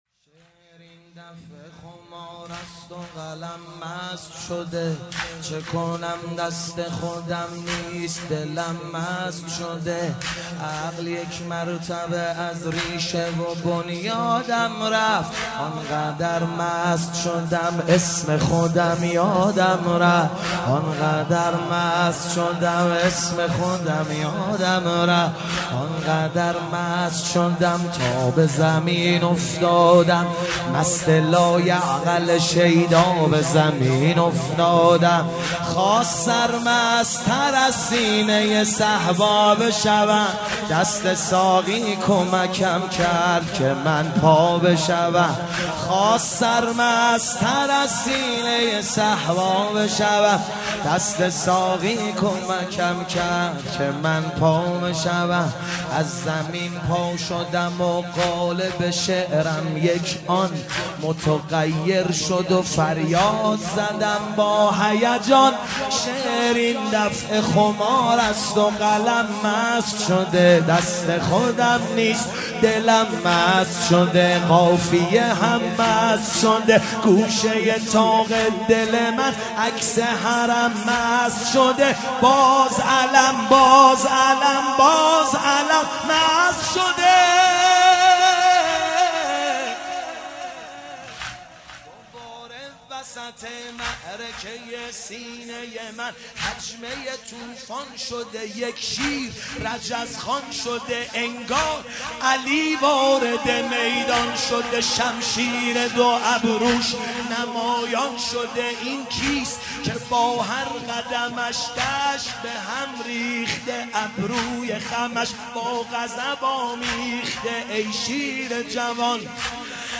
شب تاسوعا محرم 97 - واحد - دلم مست شده